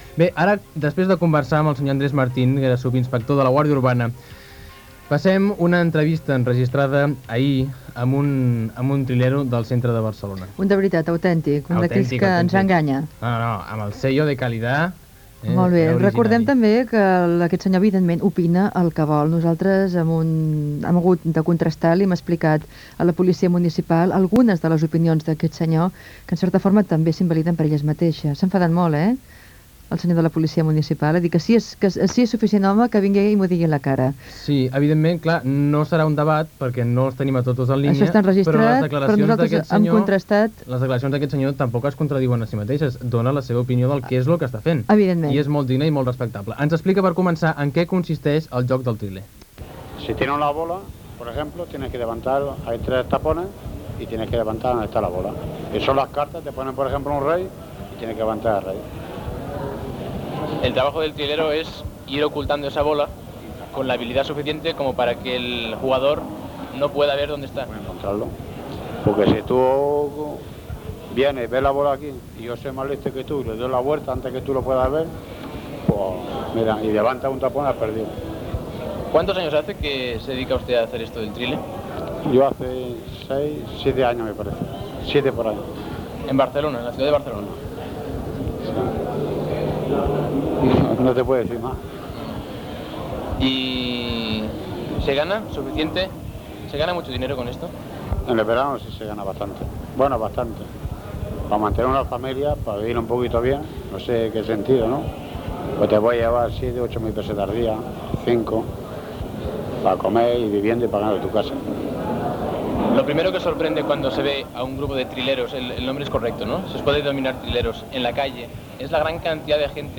Entrevista a un triler de les Rambles de Barcelona
Info-entreteniment